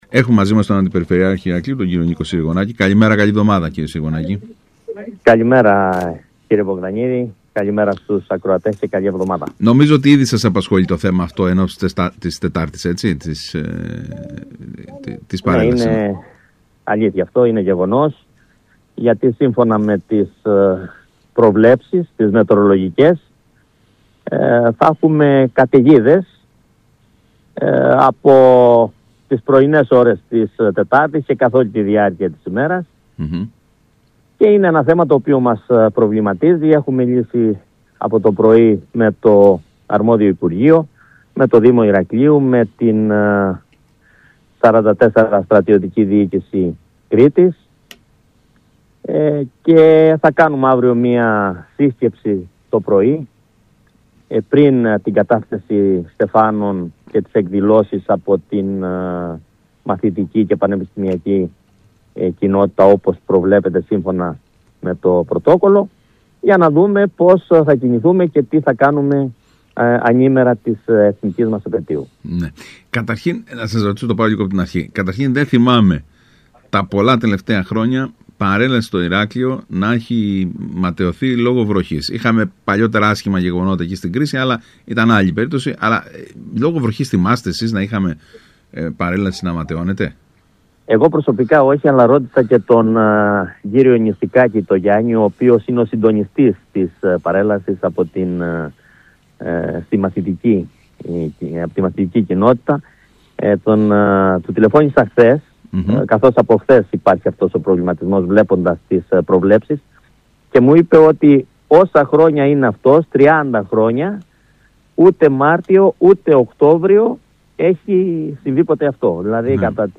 Aακούστε όσα είπε στον ΣΚΑΪ Κρήτης ο Αντιπεριφερειάρχης Ηρακλείου: